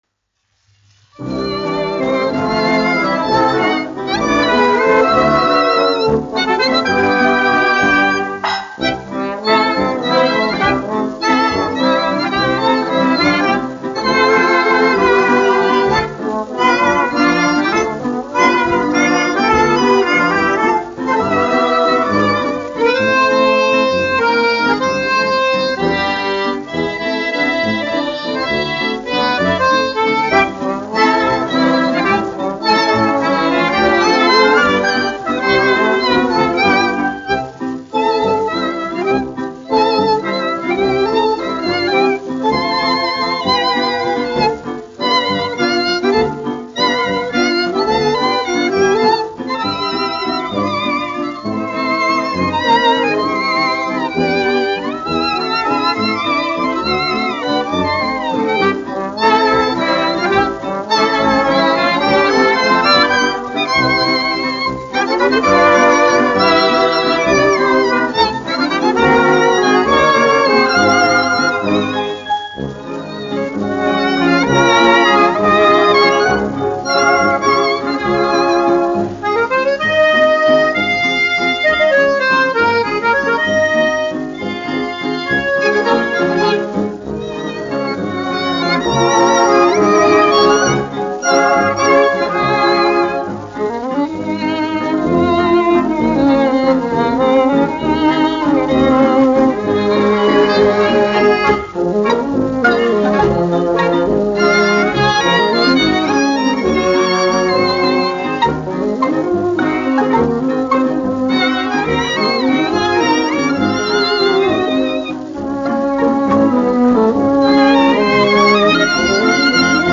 1 skpl. : analogs, 78 apgr/min, mono ; 25 cm
Kinomūzika
Skaņuplate